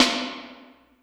59 REV-SD1-R.wav